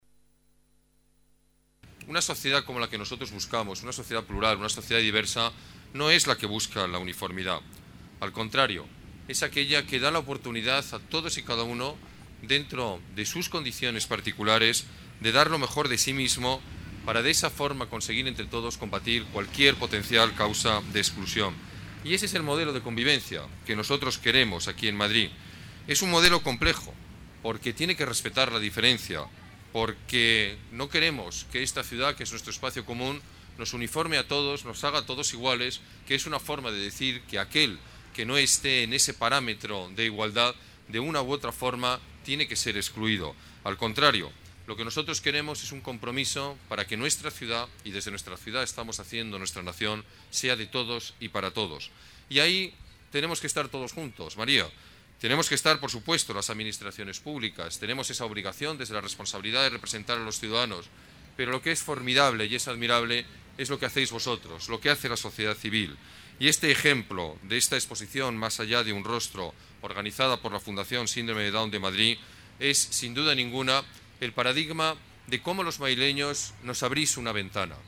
Nueva ventana:Declaración de Gallardón en la exposición de la Fundación Síndrome de Down: Madrid ciudad plural y diversa.